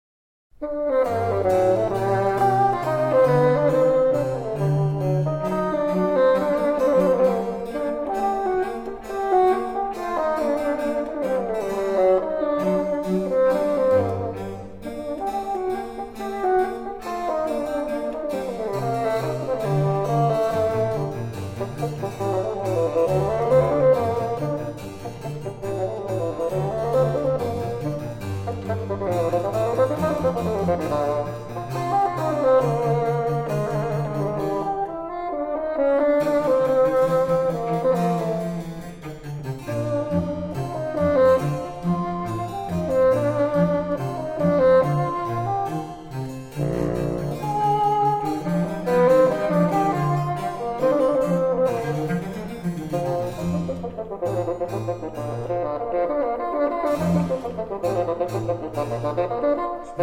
cello
piano and harpsichord